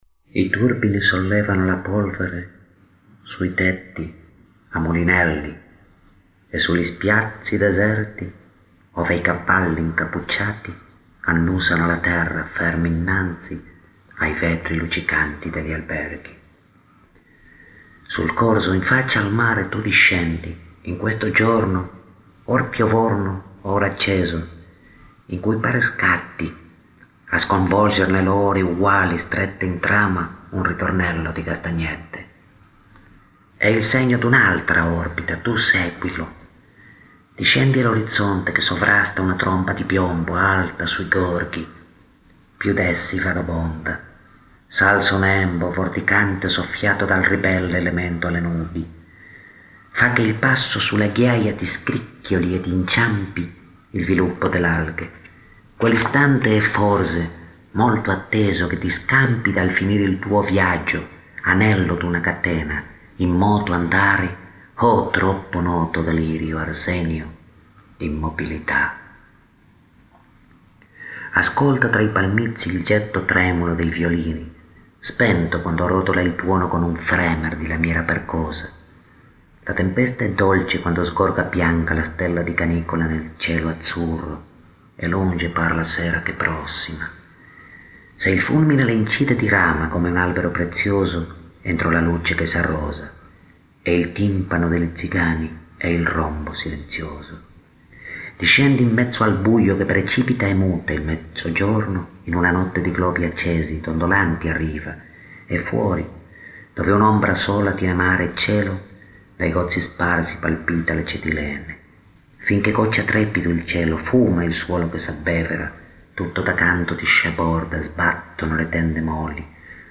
Recita: